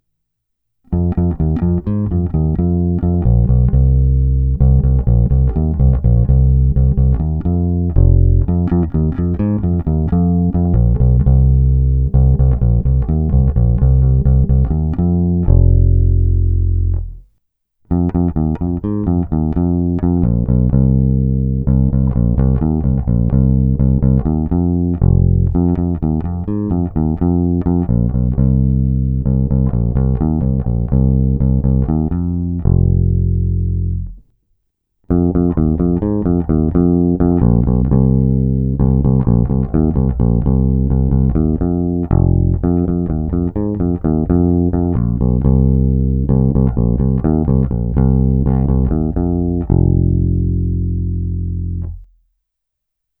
Zvuk je tučný, na jeho pozadí i přes tupé struny slyším zvonivé vrčení typické právě pro modely 1957-1959.
Není-li řečeno jinak, následující nahrávky jsou provedeny rovnou do zvukové karty a kromě normalizace ponechány bez úprav. Tónová clona vždy plně otevřená.